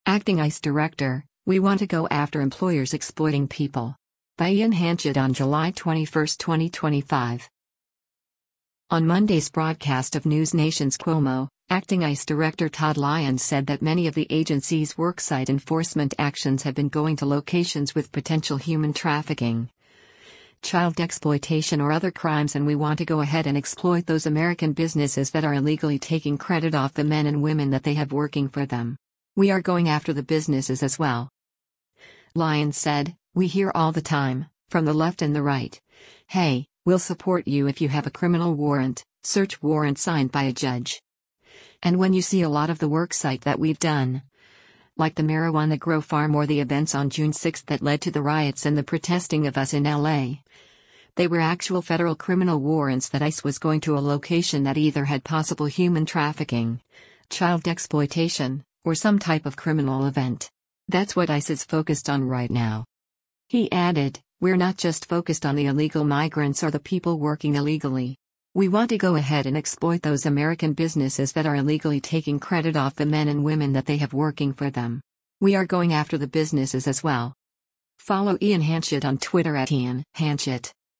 On Monday’s broadcast of NewsNation’s “Cuomo,” acting ICE Director Todd Lyons said that many of the agency’s worksite enforcement actions have been going to locations with potential human trafficking, child exploitation or other crimes and “We want to go ahead and exploit those American businesses that are illegally taking credit off the men and women that they have working for them. We are going after the businesses as well.”